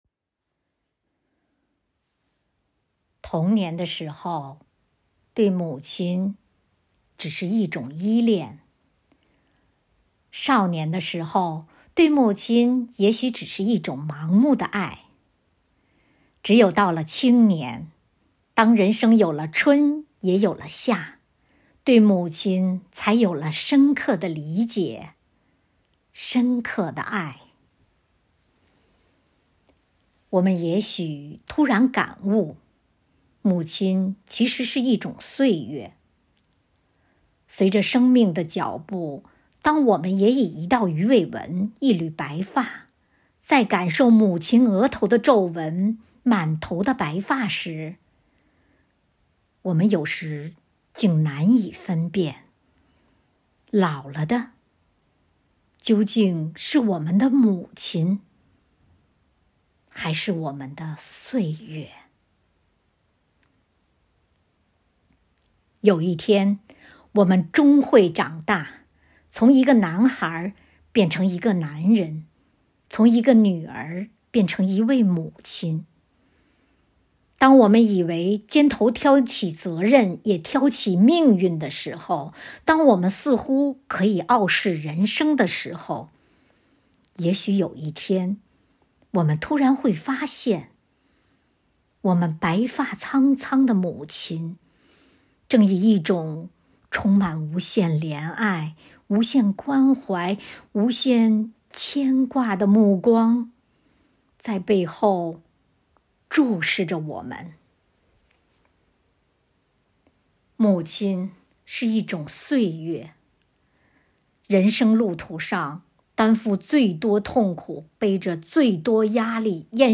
生活好课堂幸福志愿者中国钢研朗读服务（支）队第十一次云朗诵会在五月开启，声声朗诵、篇篇诗稿赞颂红五月，讴歌美好生活，吟诵美丽中国。
《母亲是一种岁月》朗诵